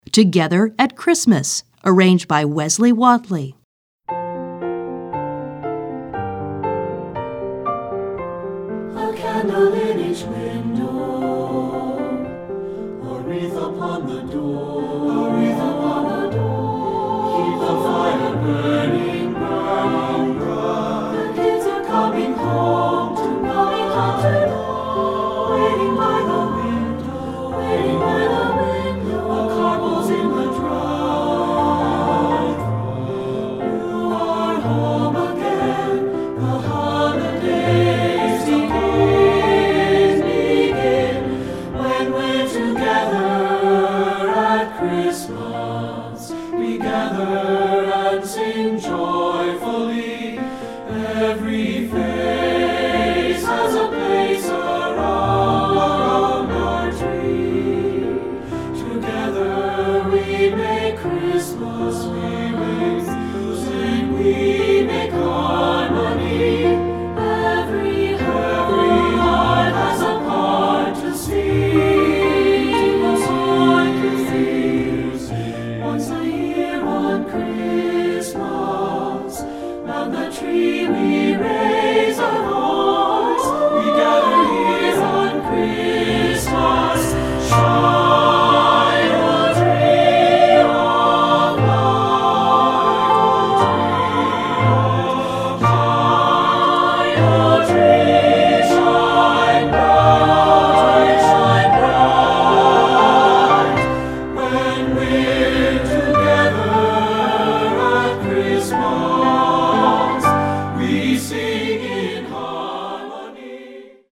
Feuillet pour Chant/vocal/choeur - SATB